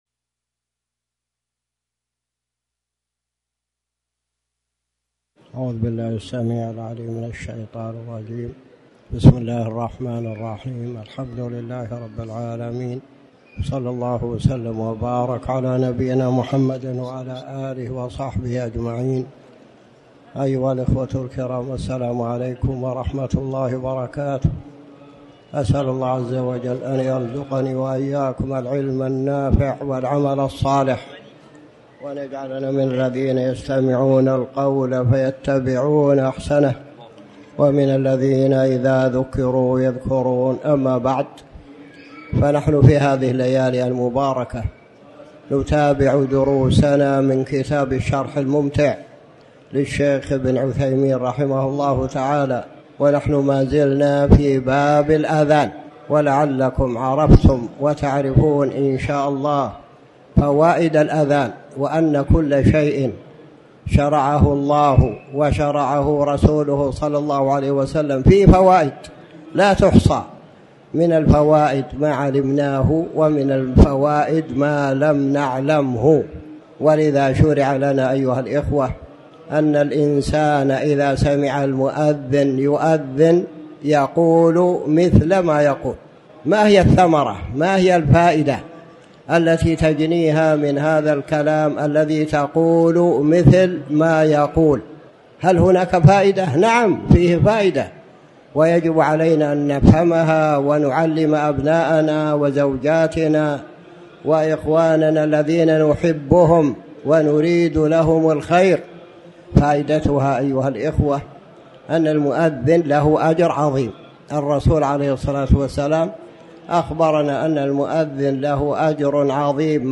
تاريخ النشر ١٩ صفر ١٤٤٠ هـ المكان: المسجد الحرام الشيخ